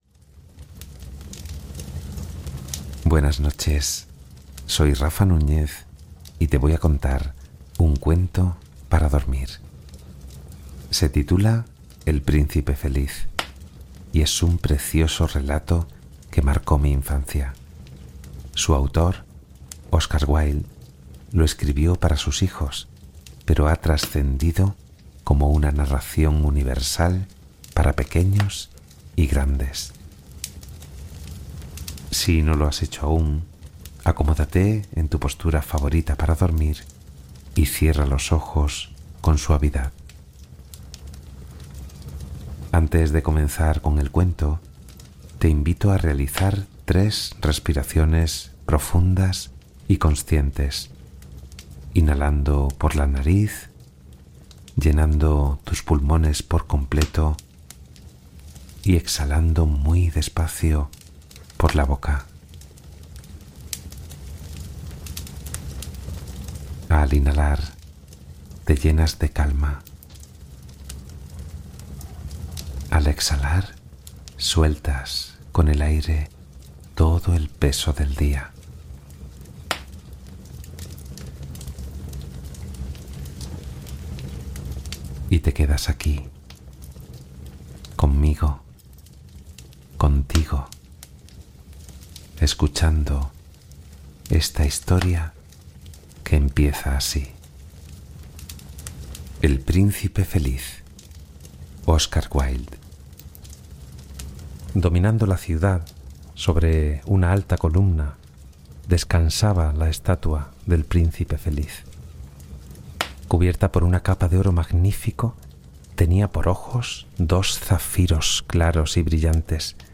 Cuento Relajante para Dormir: El Príncipe Feliz